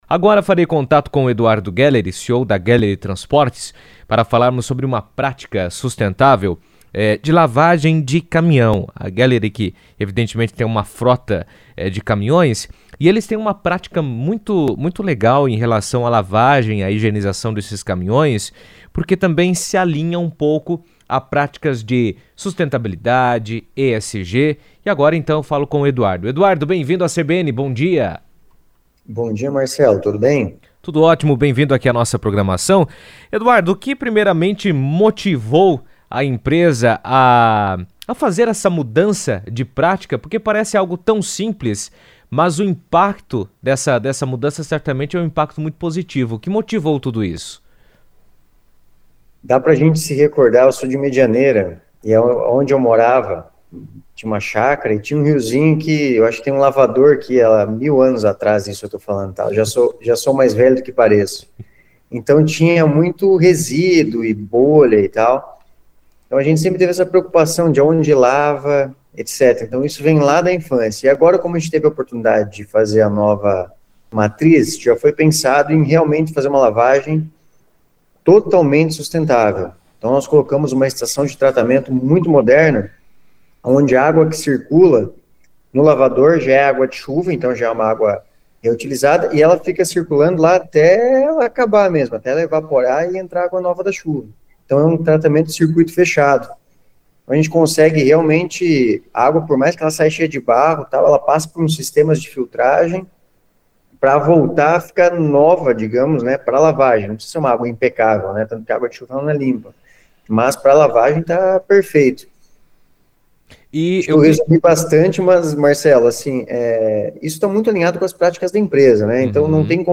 A técnica reduz significativamente o consumo de água potável, evita o descarte de resíduos e contribui para a diminuição dos impactos ambientais gerados pela limpeza da frota pesada. Em entrevista à CBN Cascavel